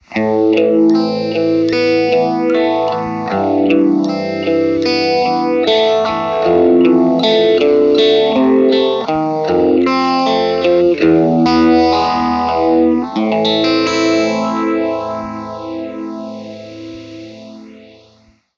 Vintage Phaser
Chitarra: Brian May Red Special
Gear: Rock You Treble Booster, Red Badger, Vox AC30
Mode: Custom (B)
Speed: 1/10